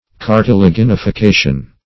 Cartilaginification \Car`ti*la*gin`i*fi*ca"tion\, n. [L.